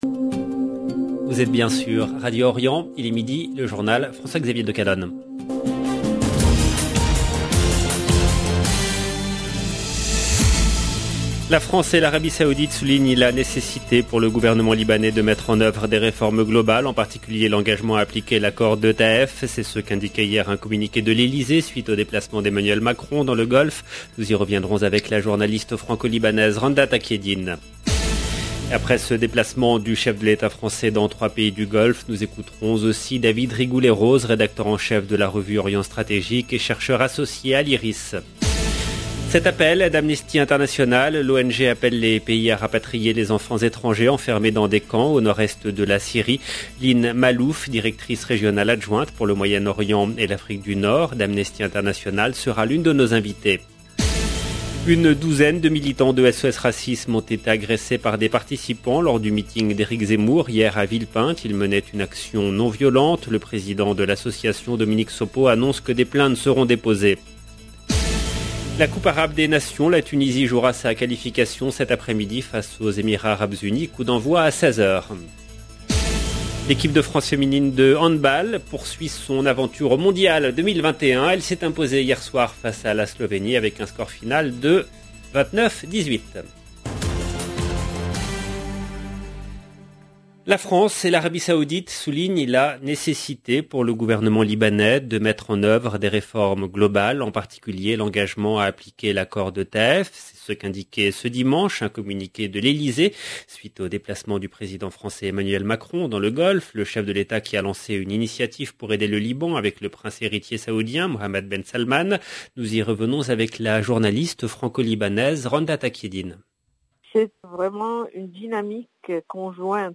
LE JOURNAL EN LANGUE FRANCAISE DE MIDI DU 6/12/21